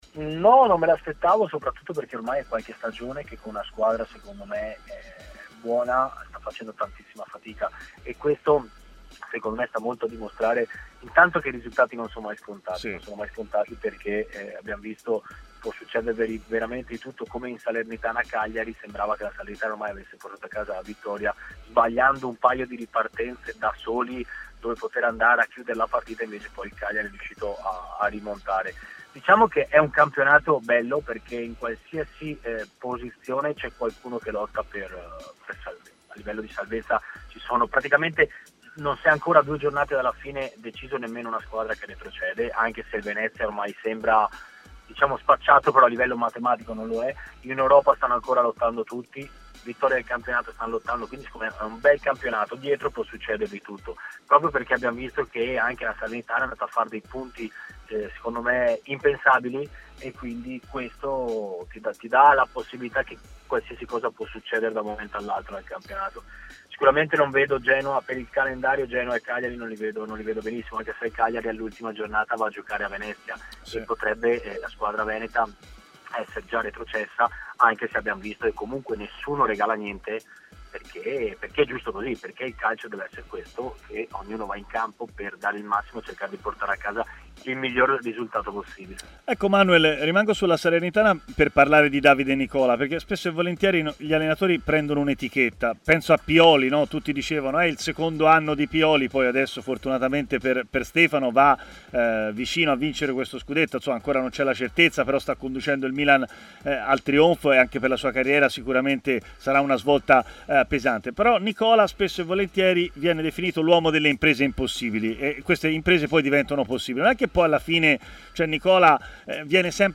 Cosi l'ex difensore Manuel Pasqual ha parlato della lotta salvezza su Tmw Radio